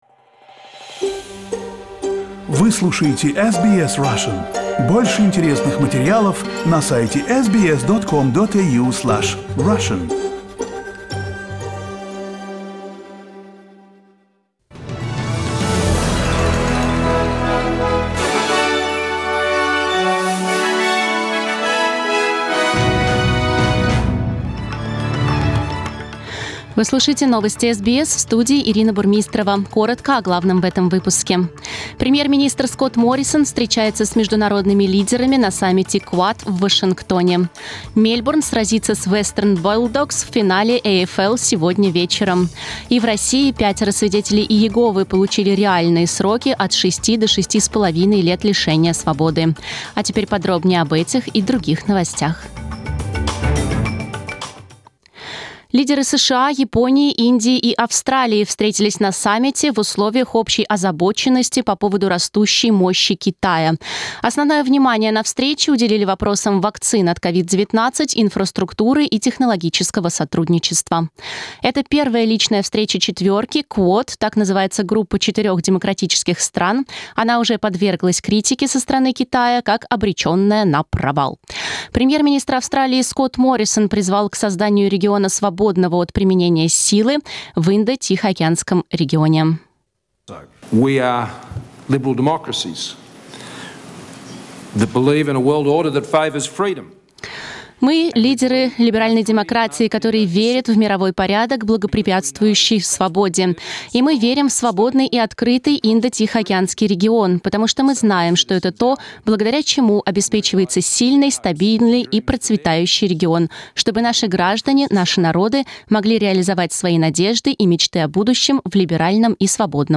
Listen to the top news headlines from SBS Russian.